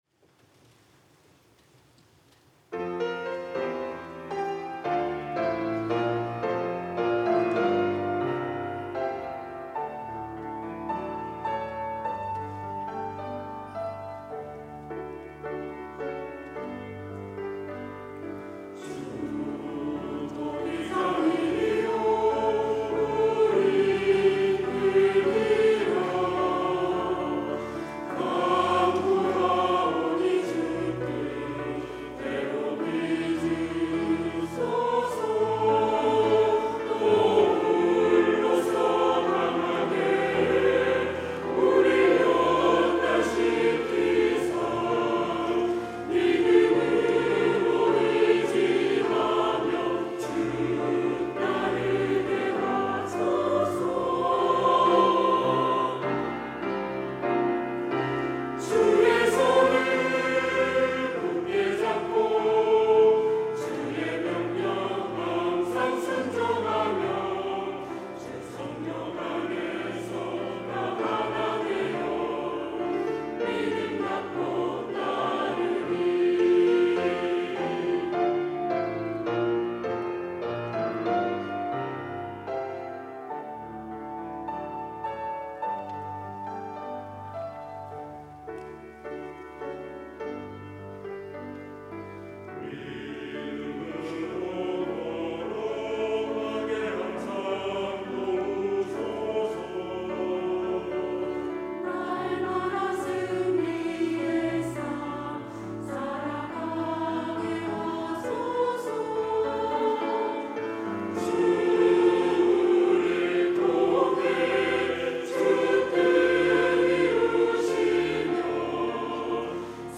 시온(주일1부) - 믿음으로 나아가
찬양대